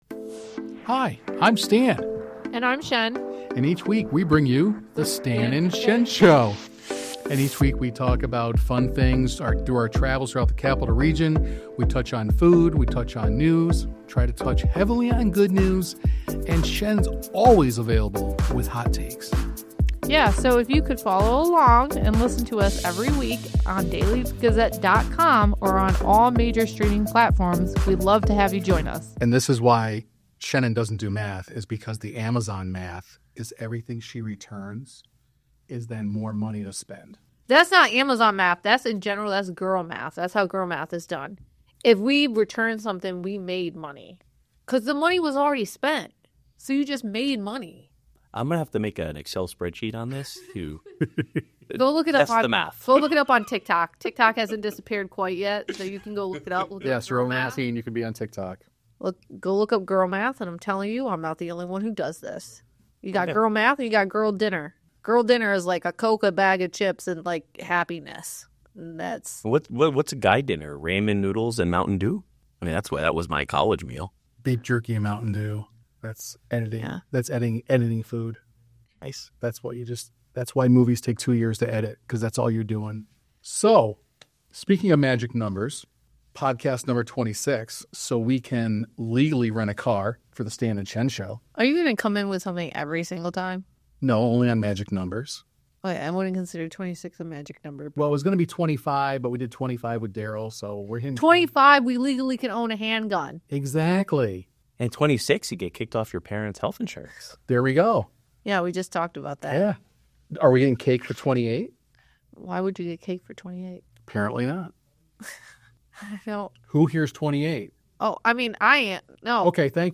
Over pork chops and braised beans, Martha looks back at the 1985 classic ‘The Goonies,’ why she feels embraced in London more than Hollywood, and she reflects on her special relationship with the late River Phoenix. This episode was recorded at Forza Wine at The National Theatre in London’s South … … continue reading